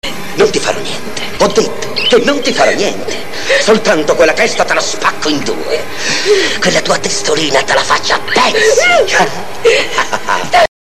La voce del personaggio dietro la porta ricorda a tratti quella di Giancarlo Giannini e quella un po’ timorosa dall’altra parte ha, in veritÃ , un piglio poco taurino.